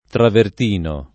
travertino [ travert & no ] s. m.